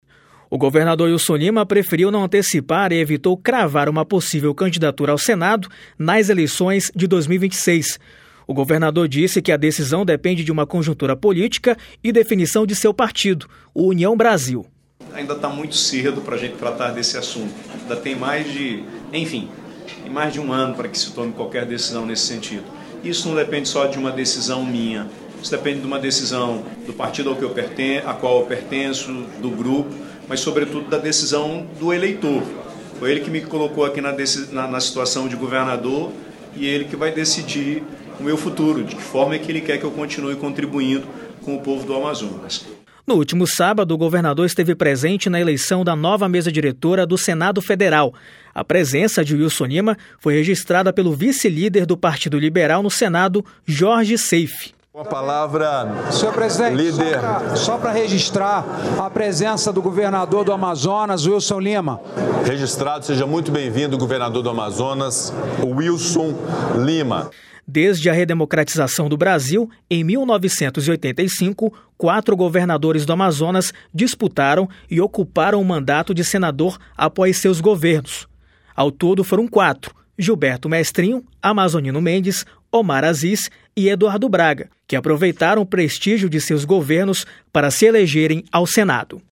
A fala aconteceu no evento de solenidade de abertura dos trabalhos legislativos na Assembleia Legislativa do Amazonas (Aleam) nessa segunda-feira (3).